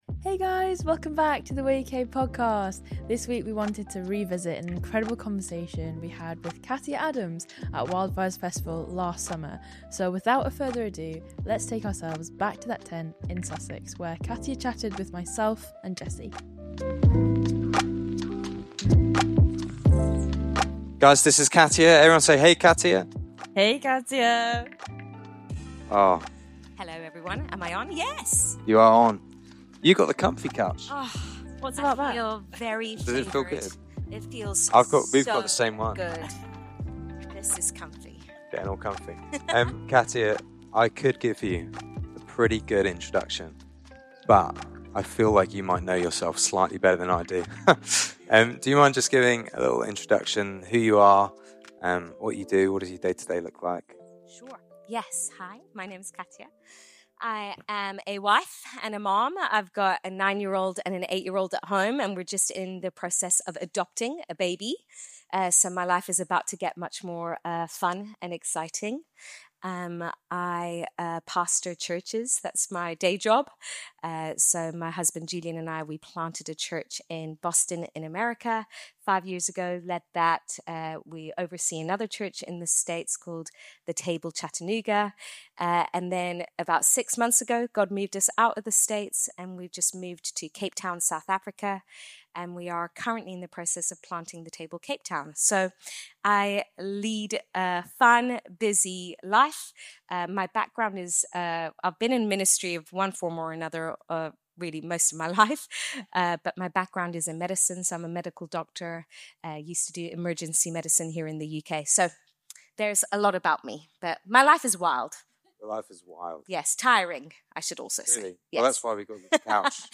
We're taking you back to an incredible conversation from Wildfires Festival last summer!